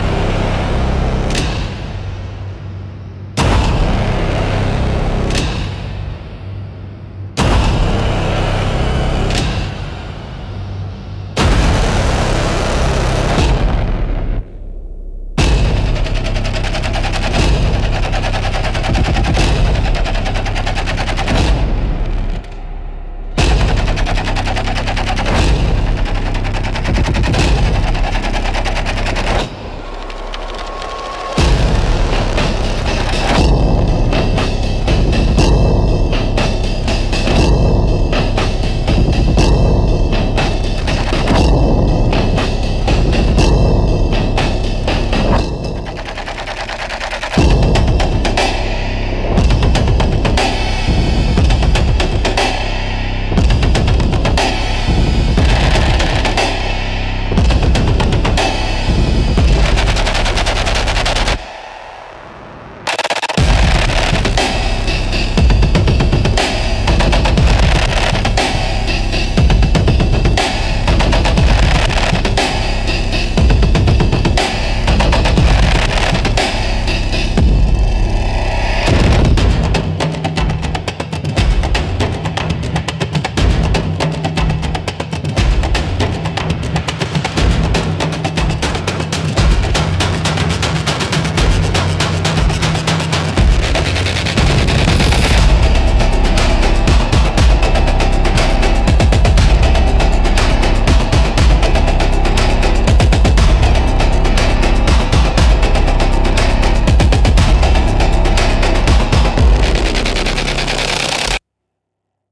Instrumental - The Forgotten Frequency -1.52